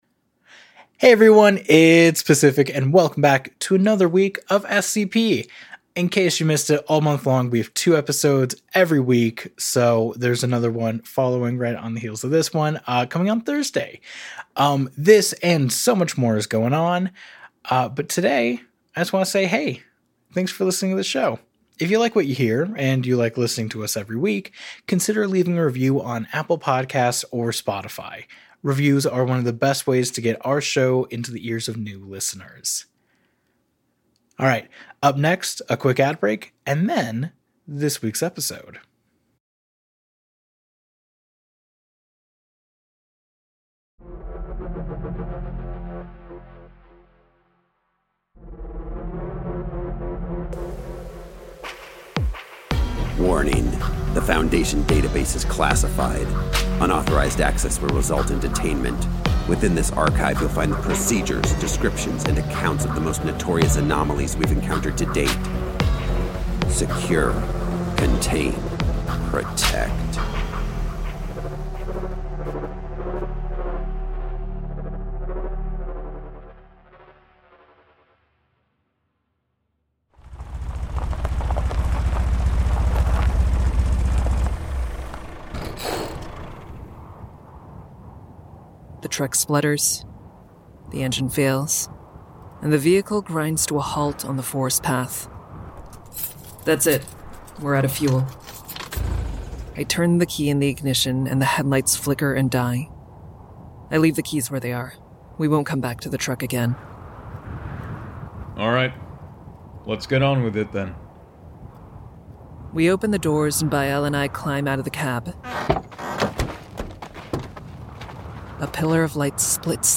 Arts, Society & Culture, Tv & Film, Drama, Fiction, Science Fiction